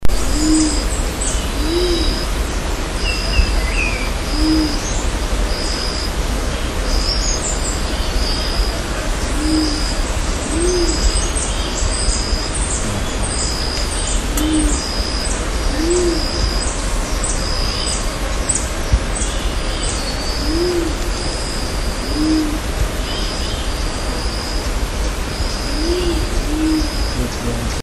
Flame breasted Fruitdove
Flame-breasted Fruit-Dove.mp3